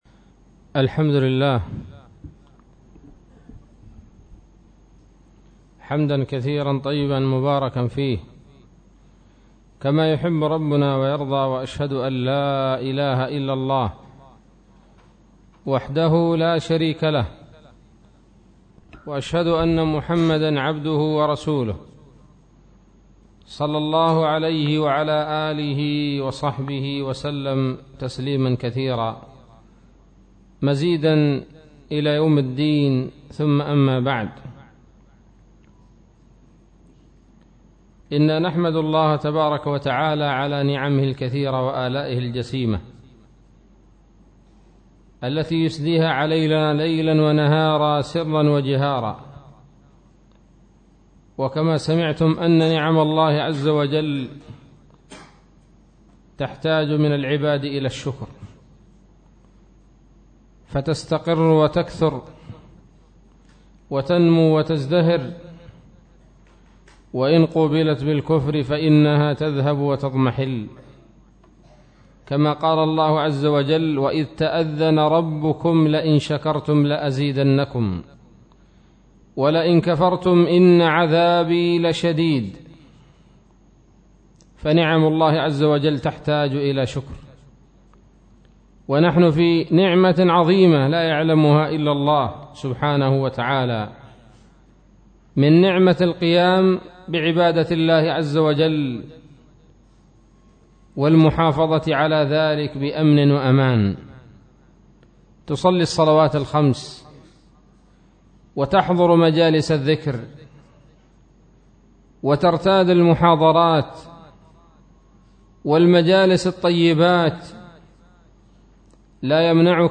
محاضرة بعنوان : ((بر الوالدين)) 8 رجب 1437 هـ